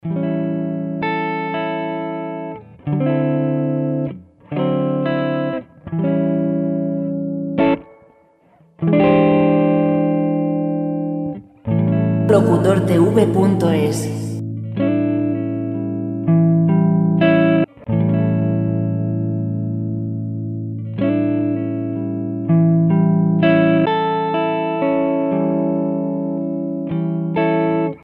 musica guitarra sin copyright musica pop sin copyright